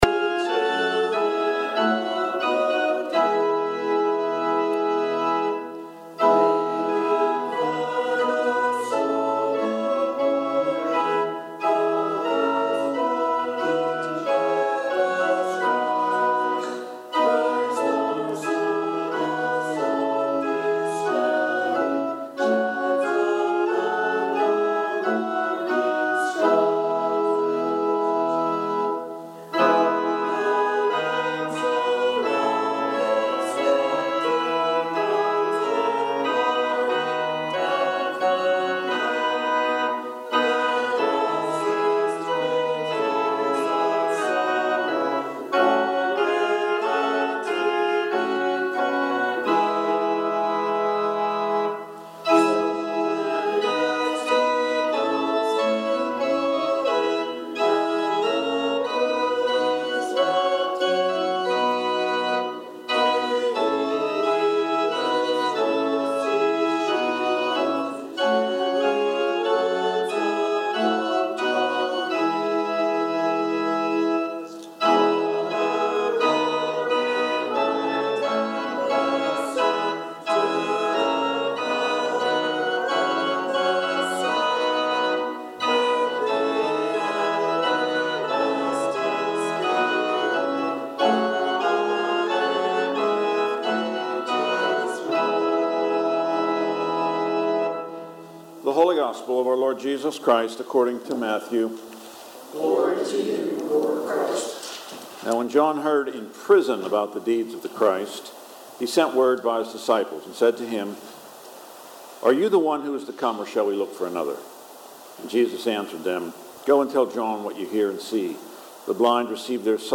Gospel and Sermon December 14, 2025 – Saint Alban's Anglican Church